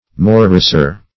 Morricer \Mor"ri*cer\, n. A morris dancer.